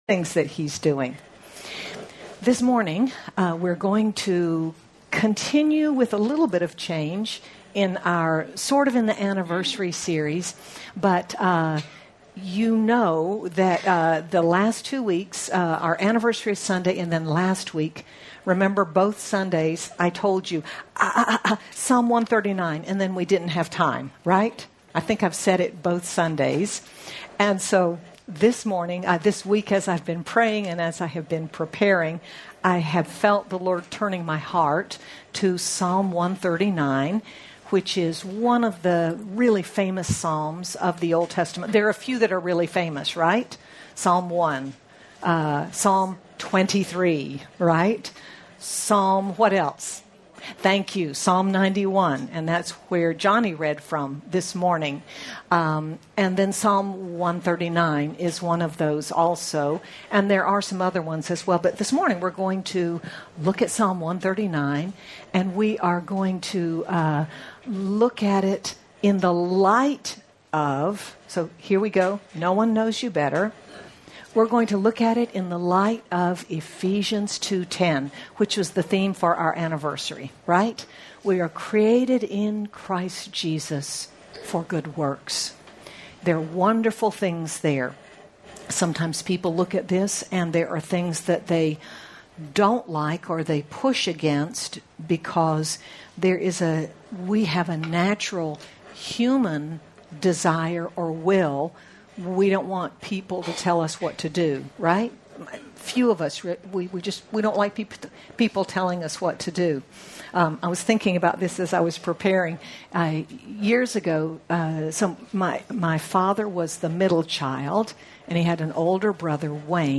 Be encouraged by the truths of Psalm 139 that paint a picture of a God who knows you completely and will always be with you. Sermon by